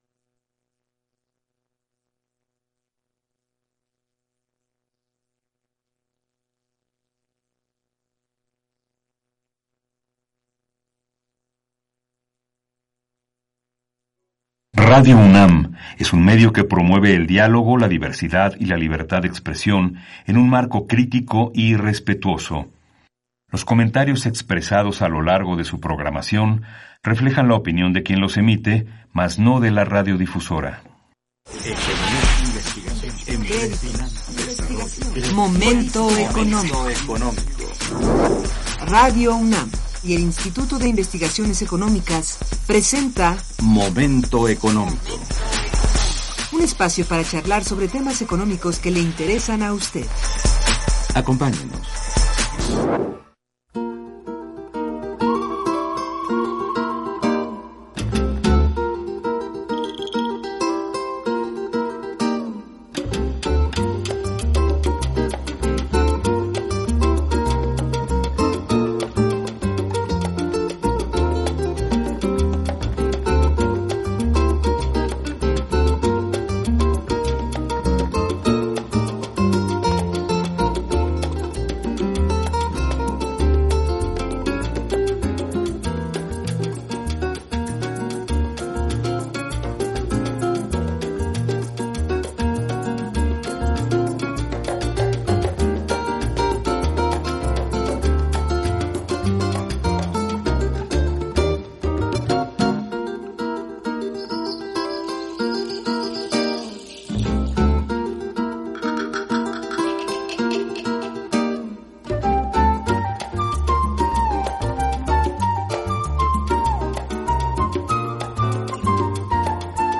Programa de Radio Momento Económico